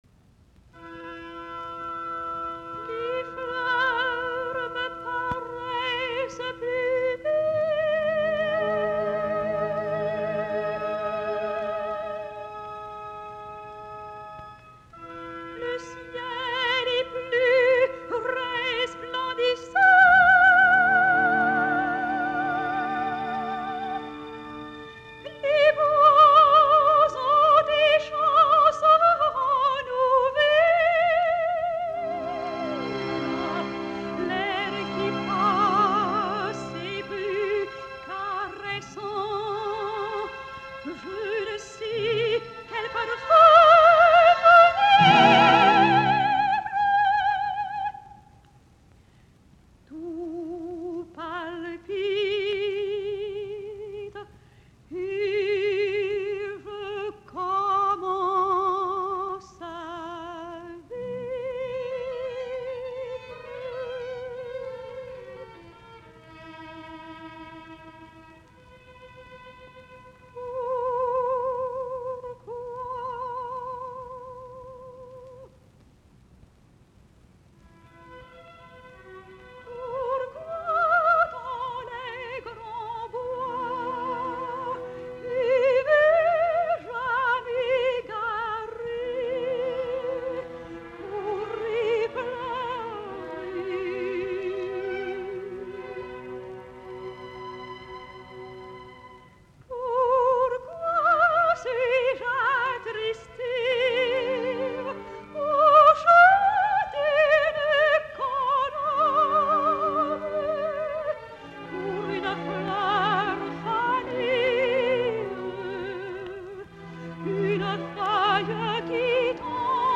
musiikkiäänite
sopraano